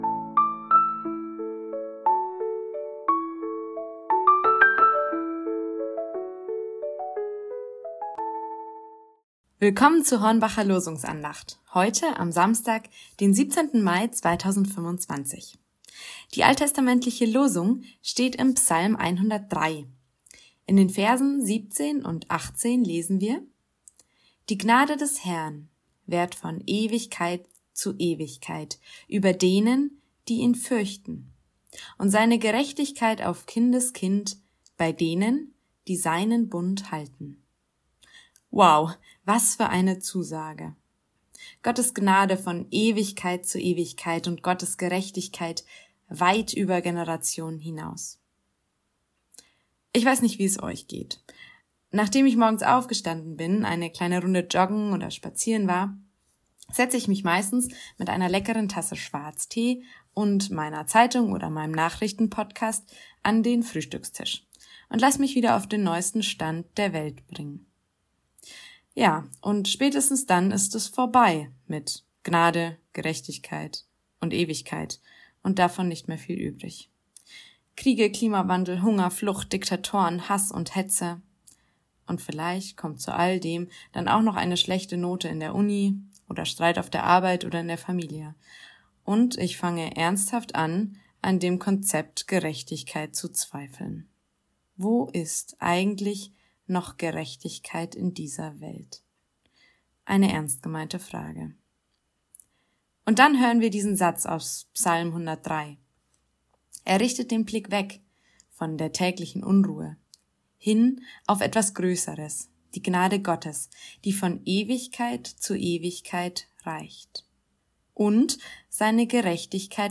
Losungsandacht für Samstag, 17.05.2025